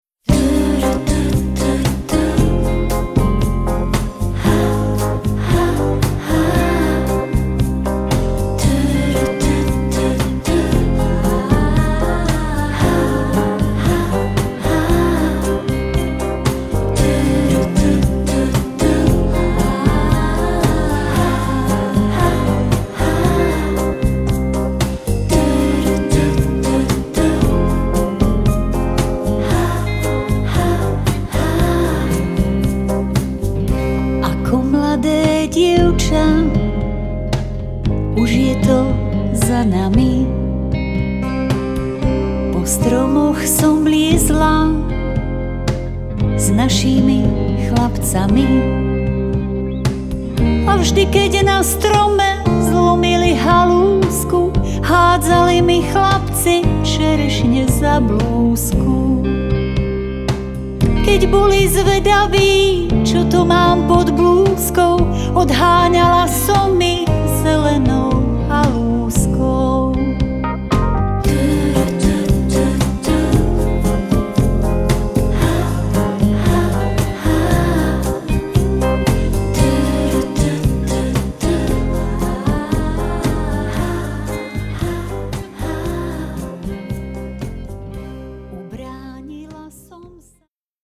obsahuje duety